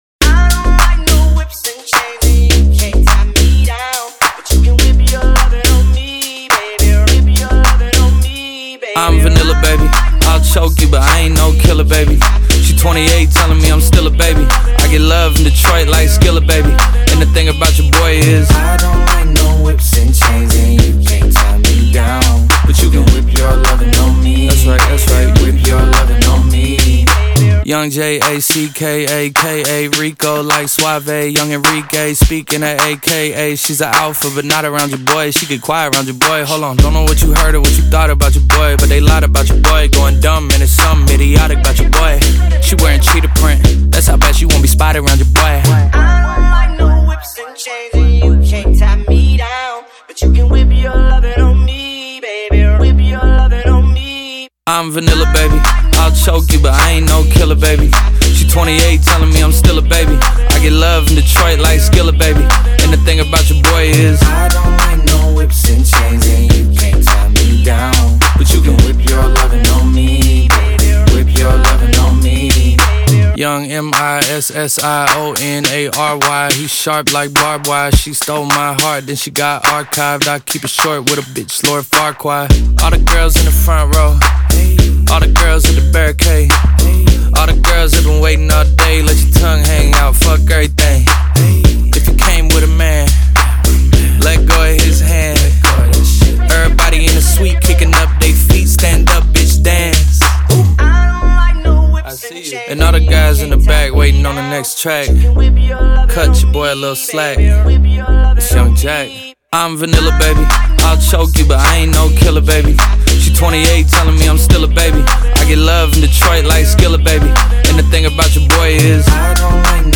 BPM105-105
Audio QualityPerfect (High Quality)
Rap song for StepMania, ITGmania, Project Outfox
Full Length Song (not arcade length cut)